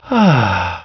DATrem2_Sigh.wav